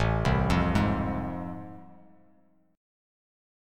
AmM7#5 chord